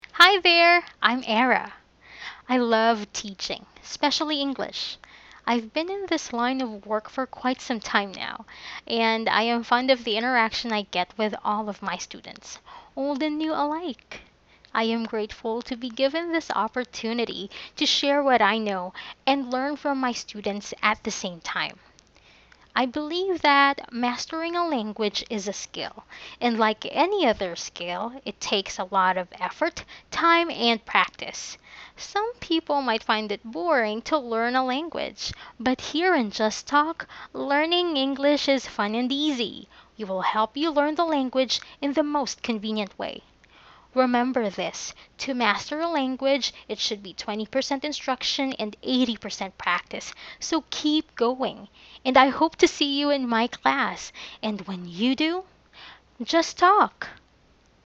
선생님소개(MP3 듣기)